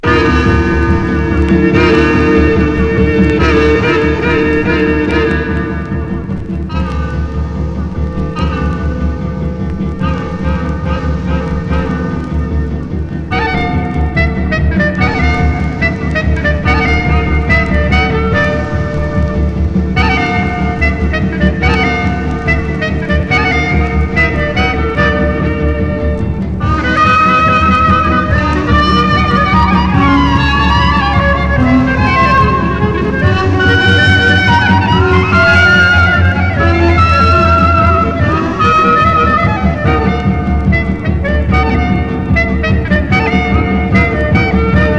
そんな1937年から1939年のニューヨークで夜な夜な炸裂したジャズの旨味がジューワーっと溢れ出してます。
Jazz　USA　12inchレコード　33rpm　Mono